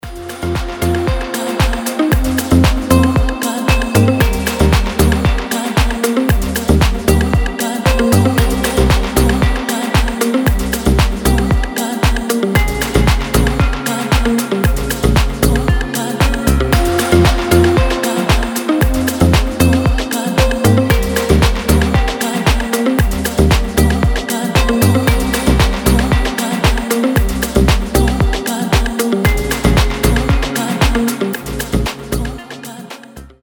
• Качество: 320, Stereo
deep house
мелодичные
спокойные
расслабляющие
Chill
Красивая deep музыка на рингтон или мелодию звонка